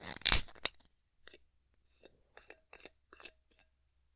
ボトル缶回して開
open_bottled_coffee.mp3